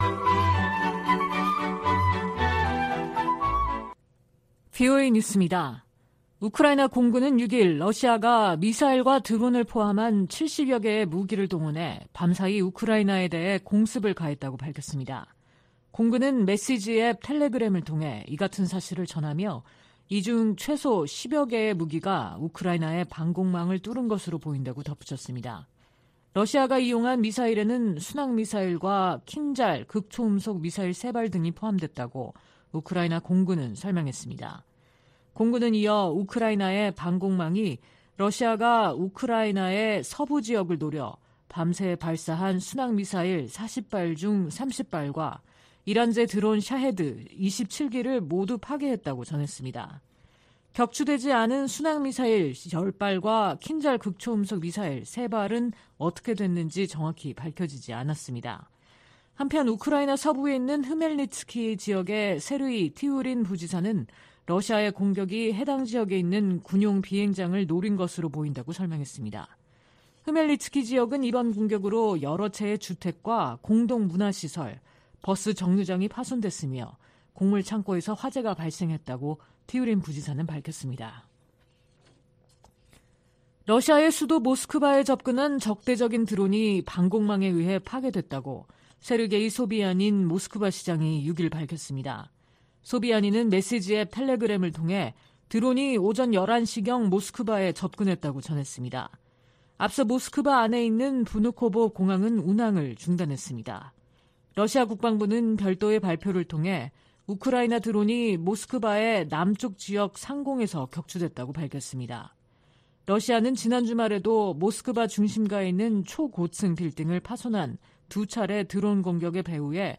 VOA 한국어 방송의 일요일 오후 프로그램 3부입니다. 한반도 시간 오후 10:00 부터 11:00 까지 방송됩니다.